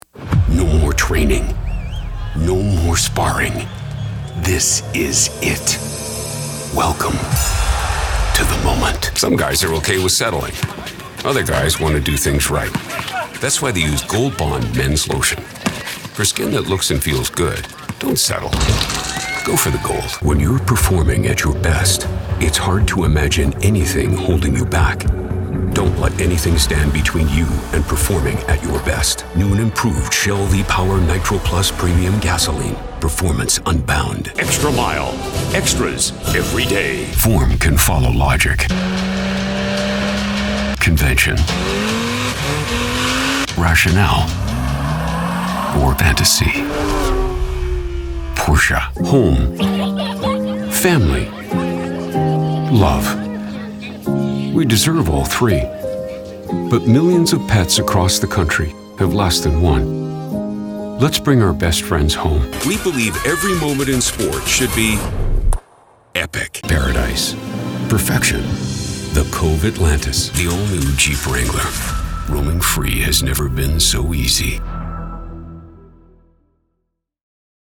VoiceOver Demos
Commercial VoiceOver Demo